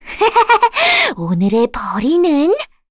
Requina-Vox_Victory_kr.wav